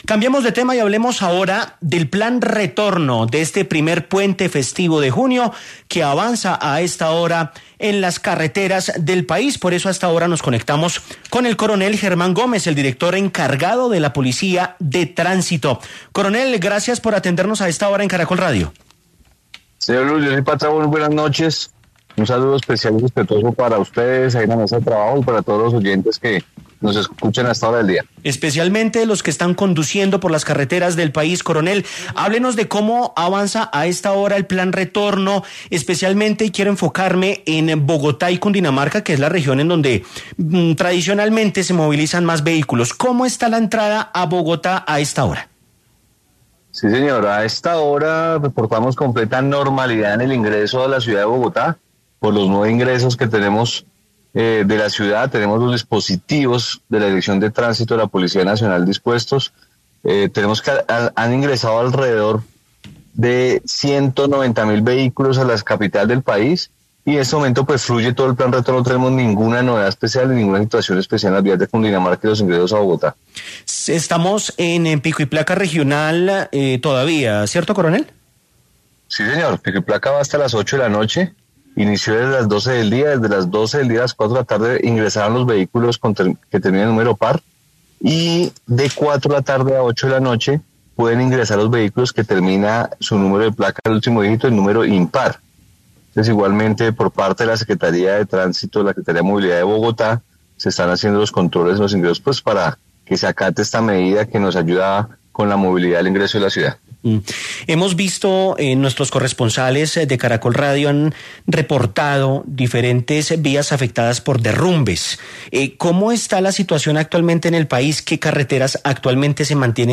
Para ampliar esta información, el coronel Germán Gómez, director encargado de la Policía de Tránsito, pasó por los micrófonos de Caracol Radio para entregar detalles sobre el comportamiento vial.